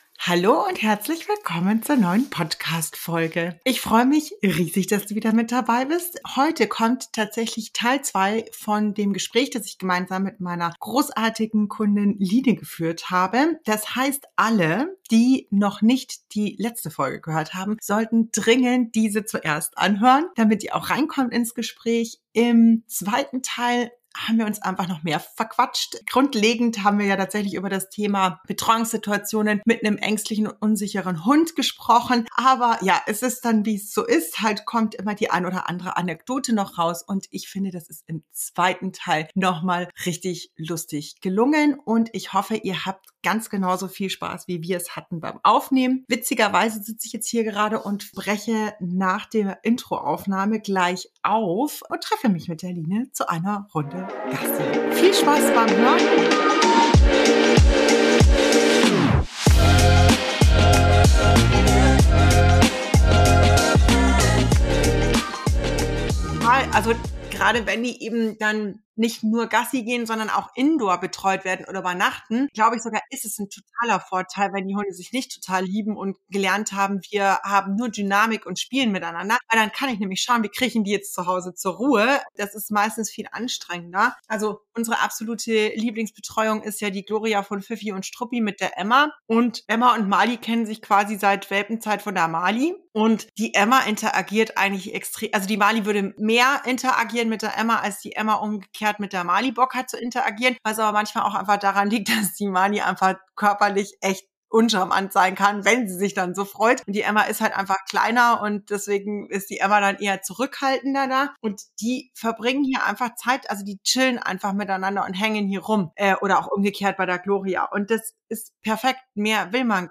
Betreuungsnetz für unsicheren Hund finden: Gespräch